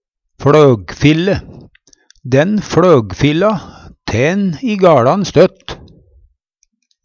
fLøgfille - Numedalsmål (en-US)